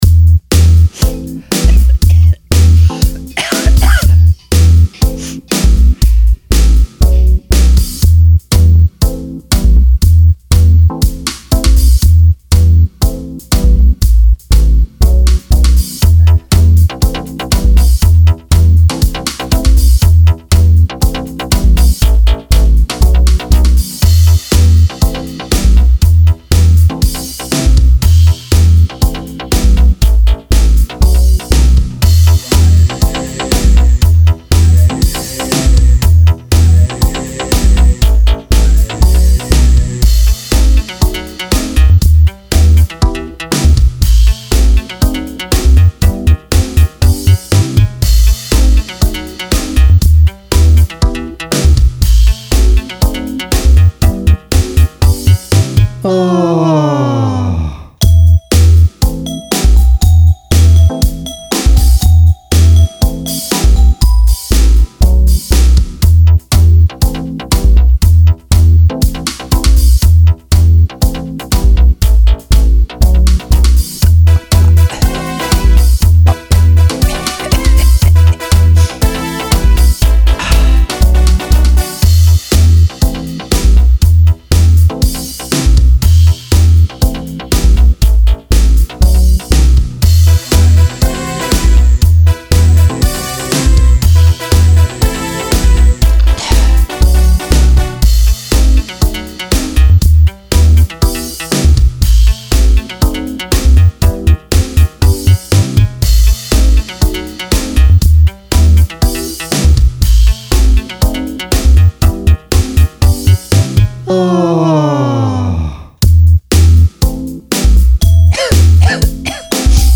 Download MP3 ohne Gesang:
download Karaoke Version 125bmp